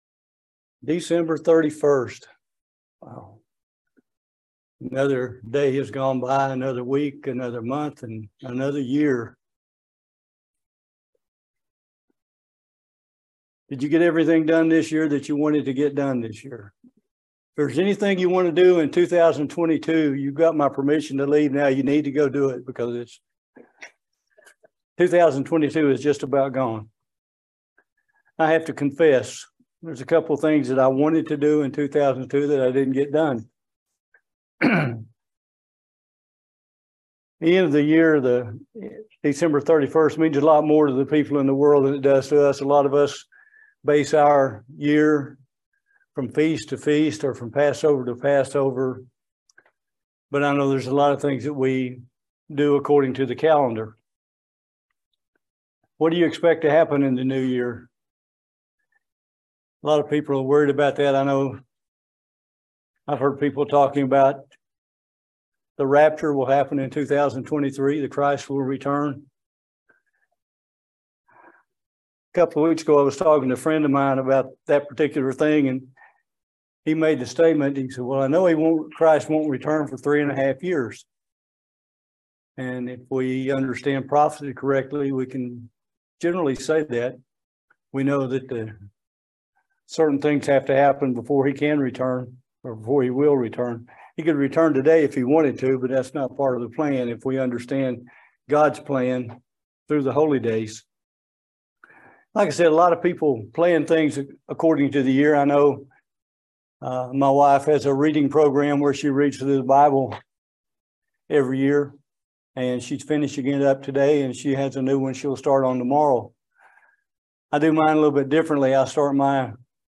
This sermon covers misunderstandings and what the scripture says about it in Philippians 2:12